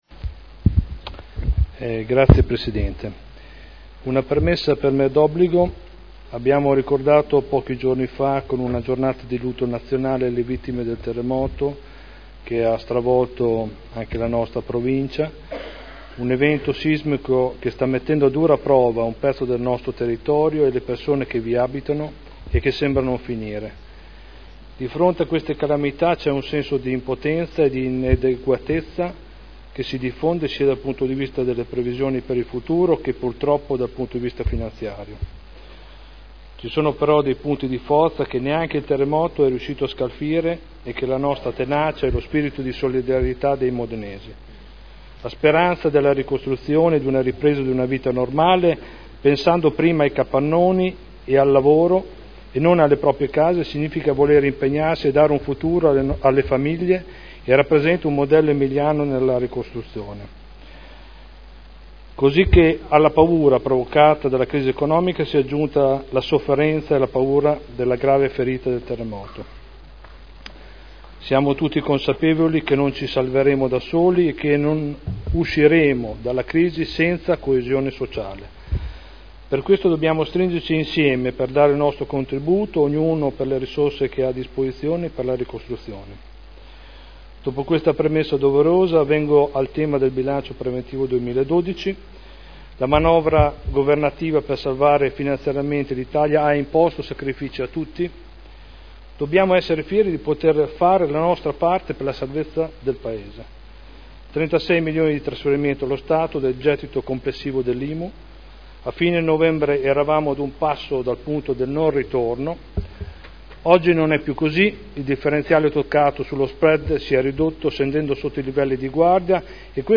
Stefano Prampolini — Sito Audio Consiglio Comunale
Seduta dell'11 giugno Dibattito su: relazione Previsionale e Programmatica - Bilancio Pluriennale 2012/2014 - Bilancio Preventivo per l'esercizio finanziario 2012 - Programma triennale dei lavori pubblici 2012/2014 – Approvazione